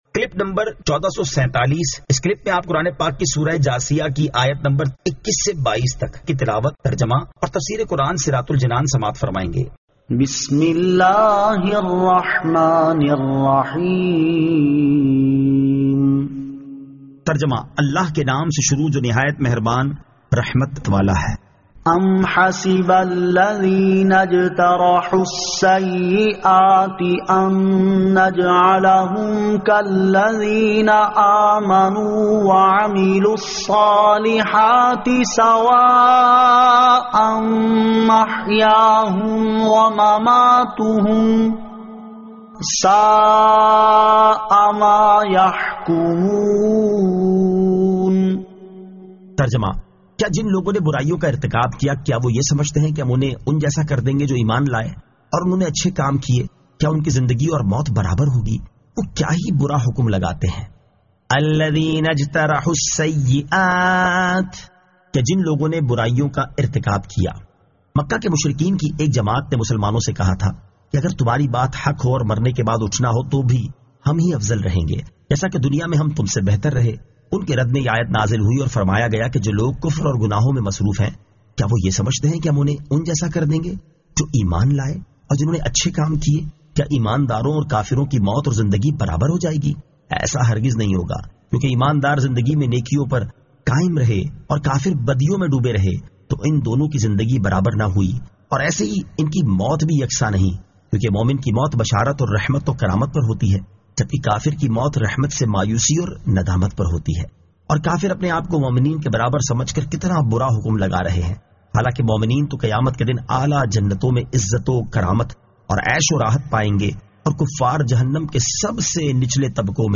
Surah Al-Jathiyah 21 To 22 Tilawat , Tarjama , Tafseer